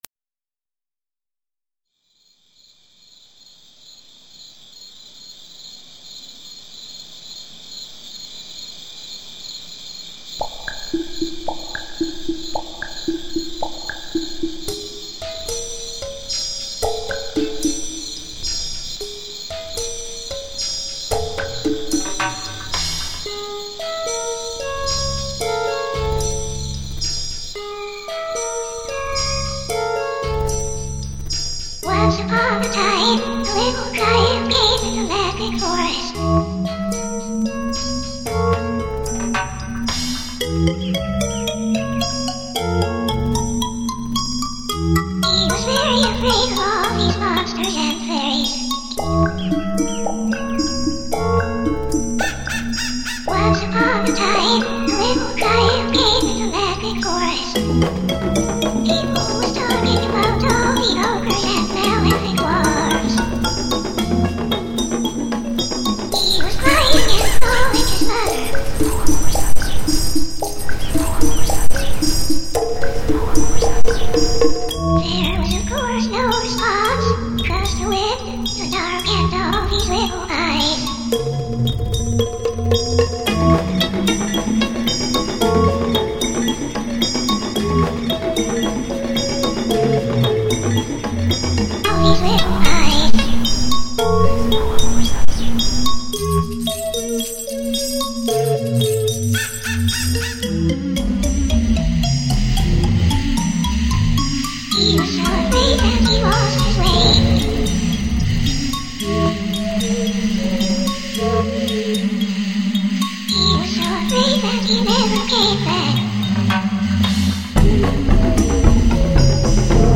File under: Weird Electronica